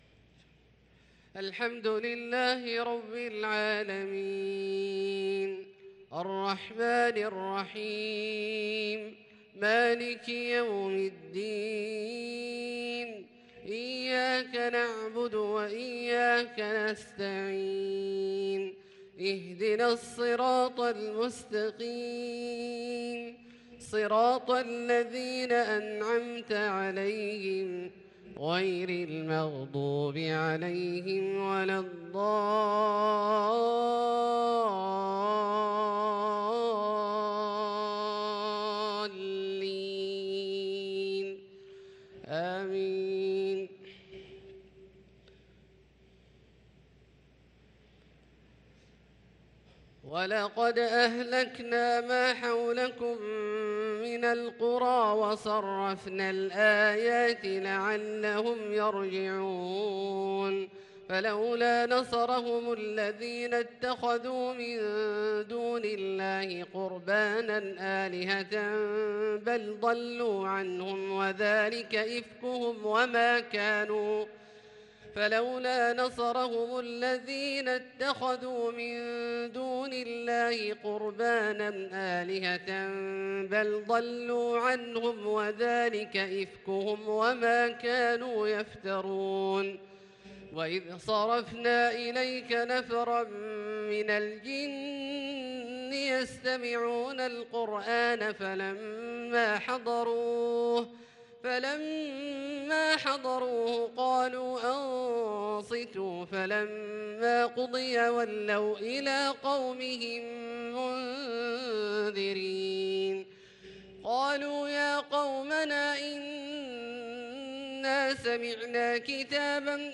صلاة المغرب للقارئ عبدالله الجهني 18 ذو القعدة 1443 هـ
تِلَاوَات الْحَرَمَيْن .